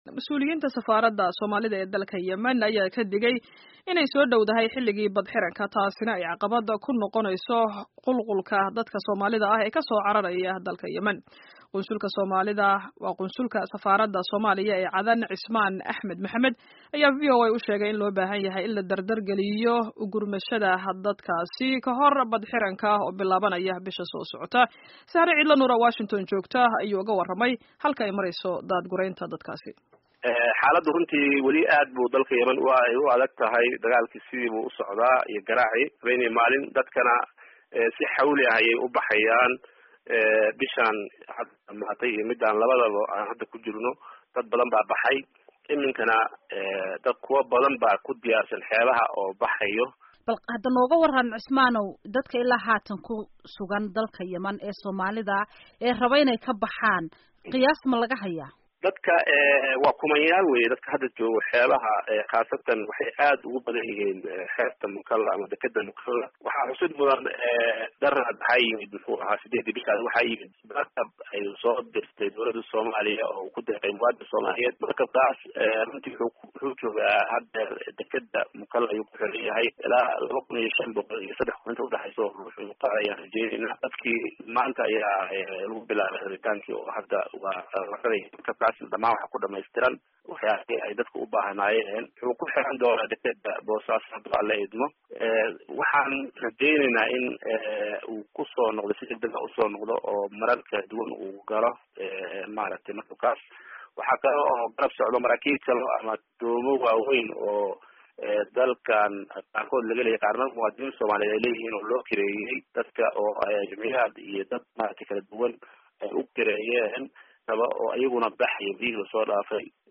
Waraysiga Qunsulka Yemen